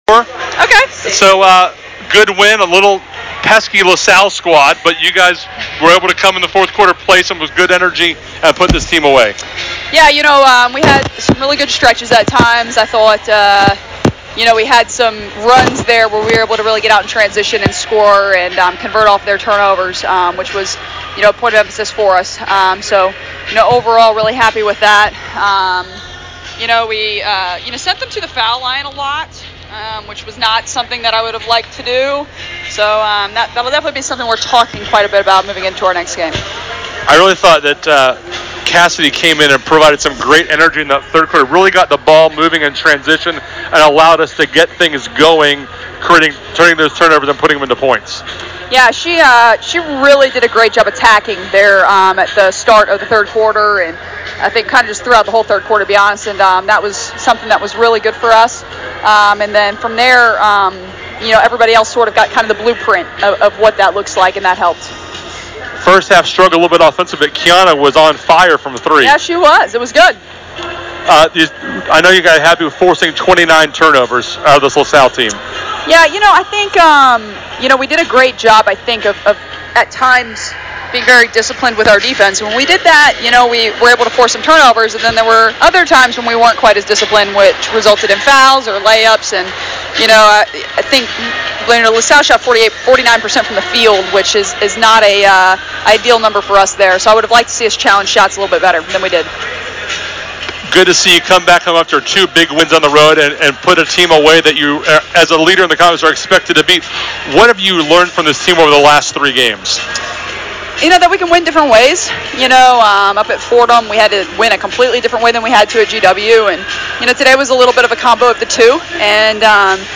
Postgame Interview
Post Game Lasalle WBB.mp3